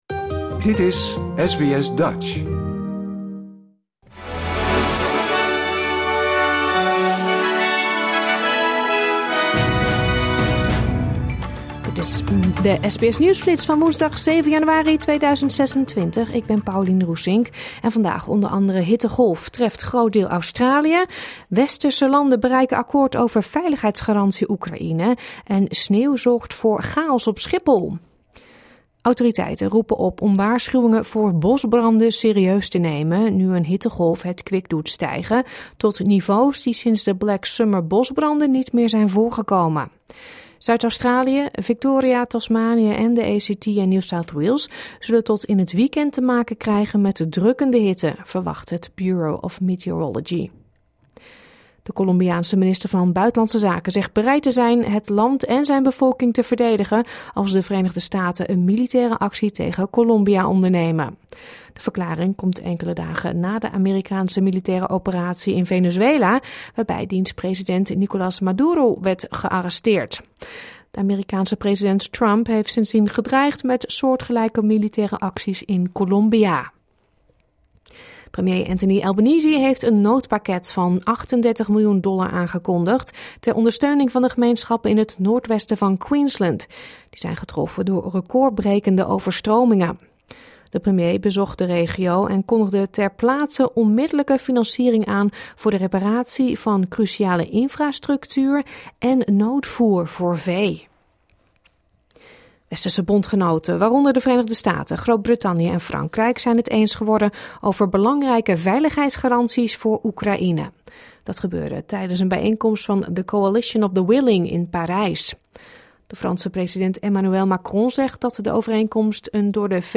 Dit is de SBS nieuwsflits van woensdag 7 januari 2026, met o.a. hittegolf treft groot deel Australië, Westerse landen bereiken akkoord over veiligheidsgarantie Oekraïne en sneeuw zorgt voor chaos op Schiphol.